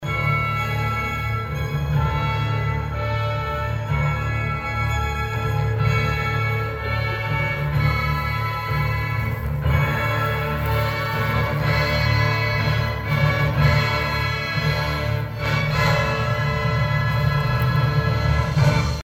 The 20th annual and final outdoor concert welcomed thousands of visitors from both the local area and well beyond it to enjoy the music of the Kansas City Symphony in the Flint Hills one final time.